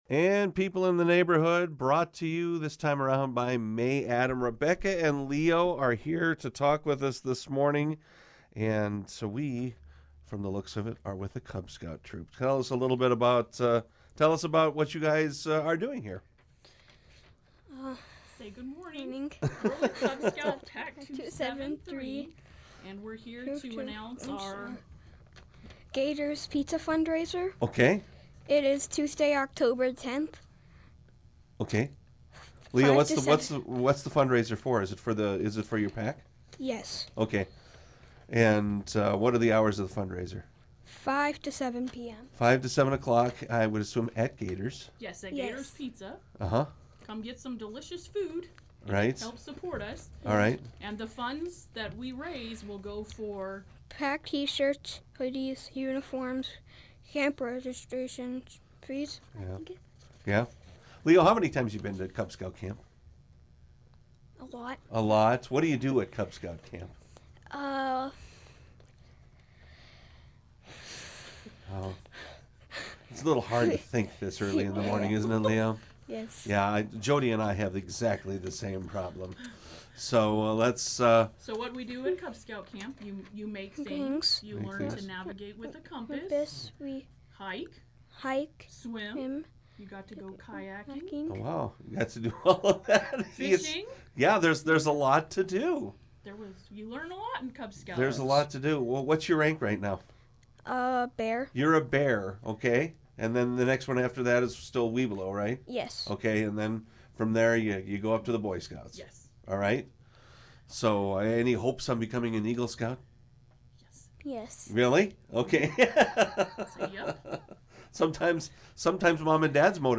chatted with representatives from Cub Scout Pack 273 about their upcoming fundraiser